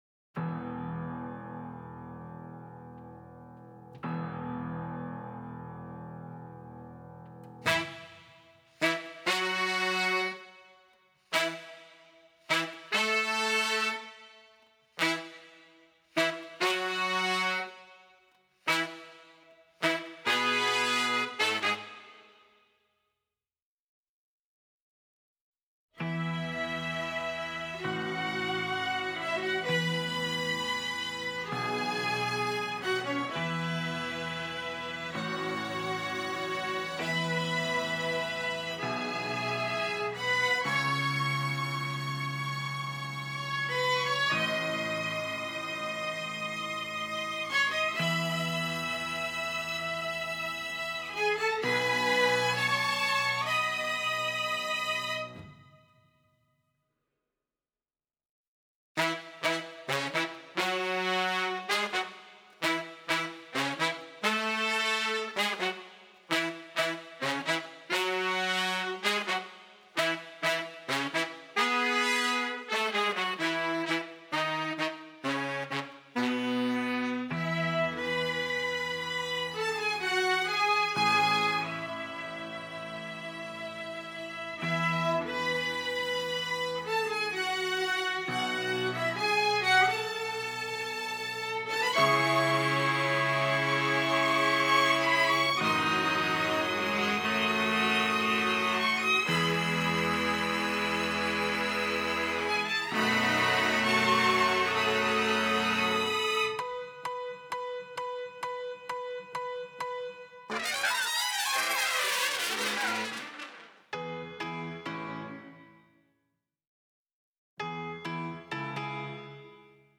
horns/strings/piano
The mix above highlights the strings and horns.